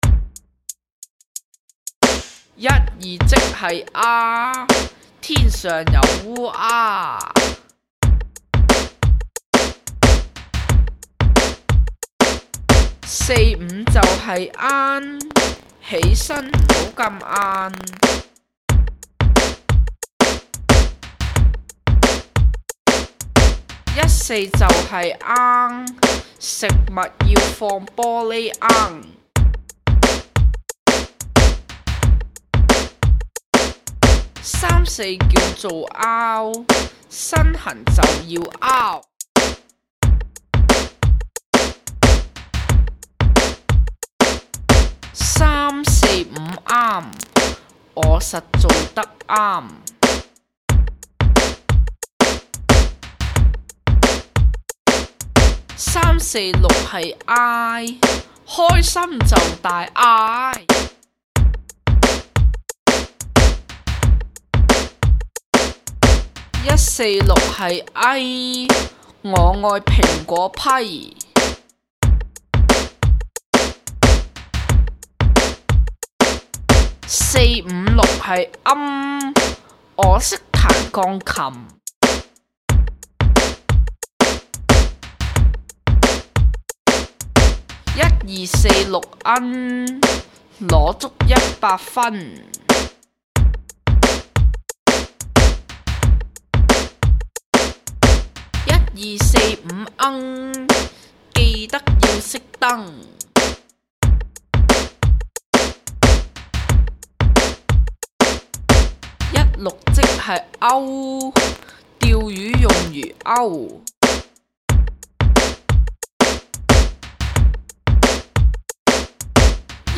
為了讓幼童能較易掌握艱深的粵音點字，教師特意創作了粵音點字歌，讓幼童能琅琅上口背誦，使其輕鬆地學習粵音點字。